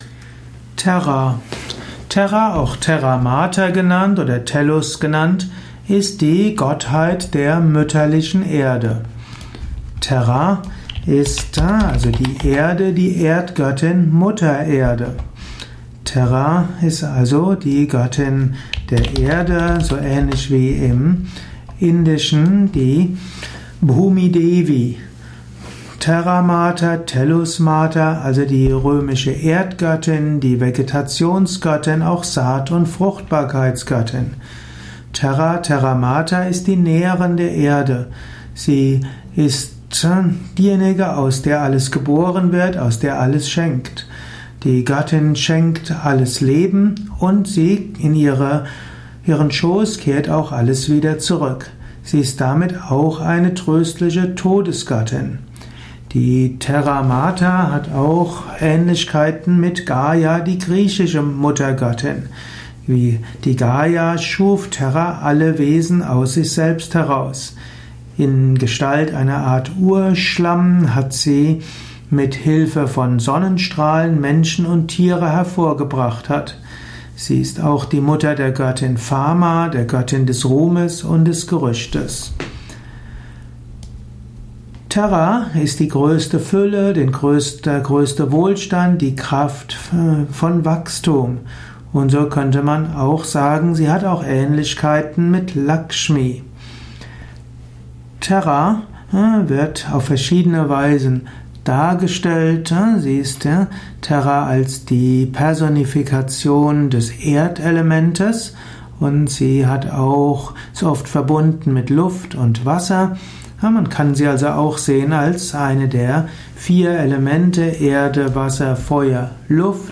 Dies ist die Tonspur eines Videos, zu finden im Yoga Wiki.